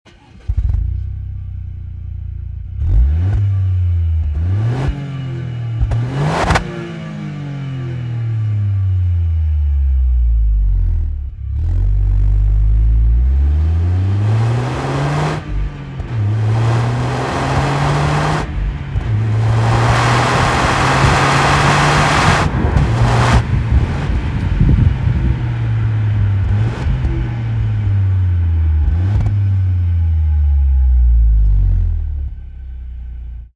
Son avec l’échappement origine :
STOCK-EXHAUST-TOYOTA-GR-YARIS.mp3